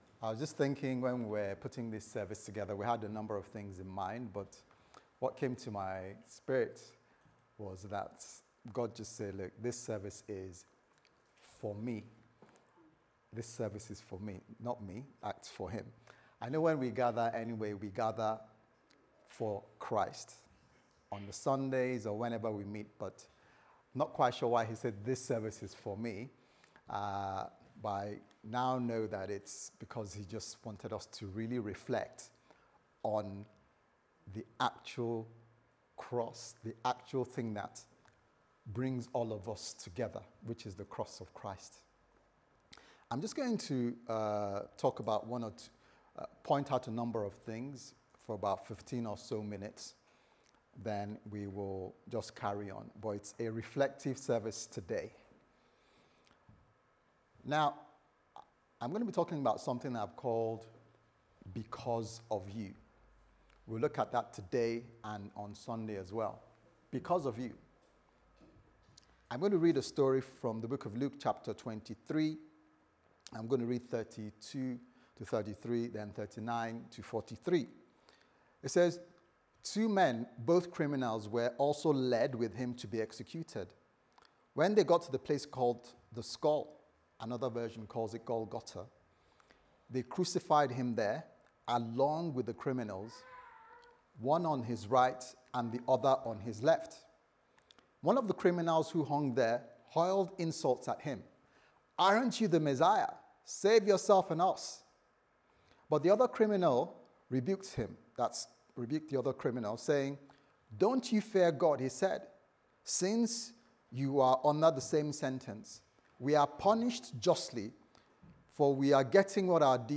Because of you Service Type: Sunday Service Sermon « The Ministry of Prayer and the Word Because of You